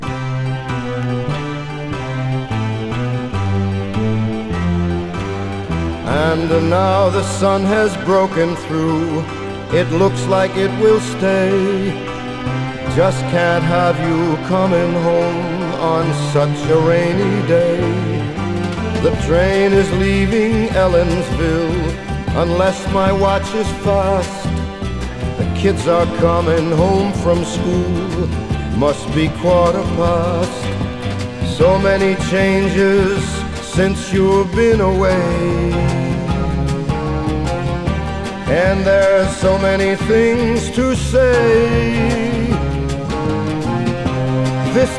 Jazz, Pop, Vocal, Ballad　USA　12inchレコード　33rpm　Stereo